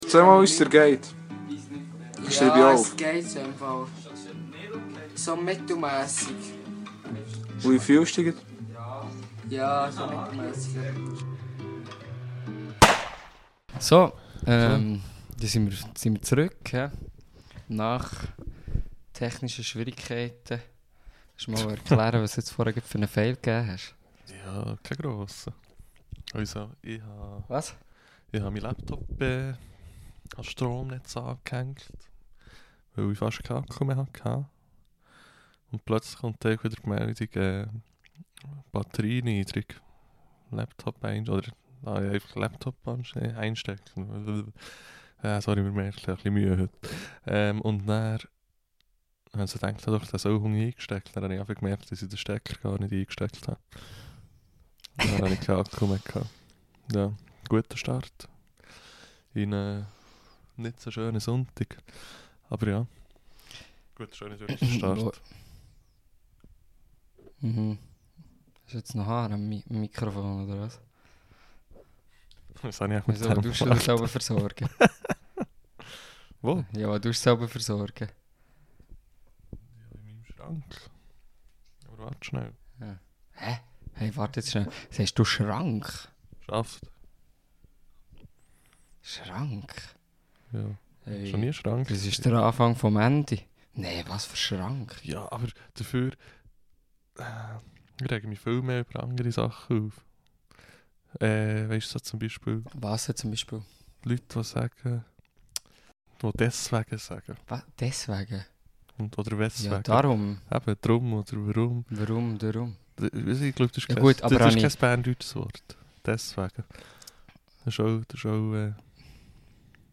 Beidi Boys verkateret aber mit viu ds verzeue.